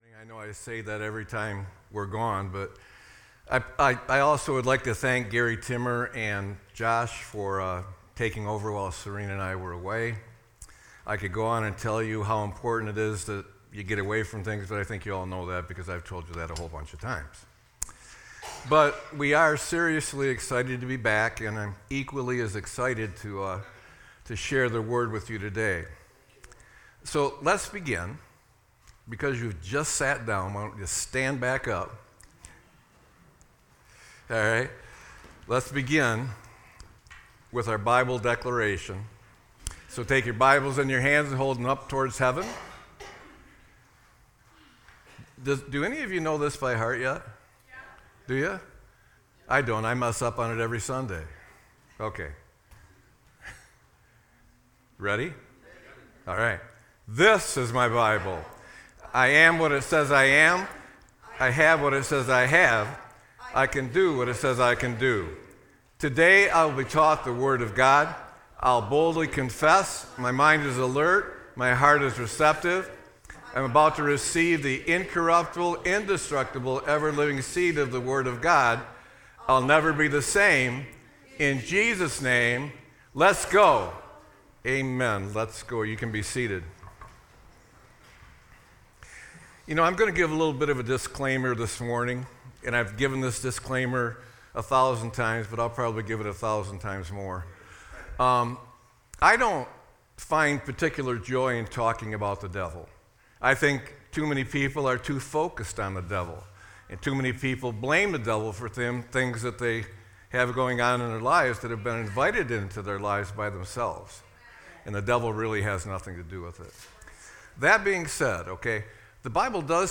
Sermon-4-06-25.mp3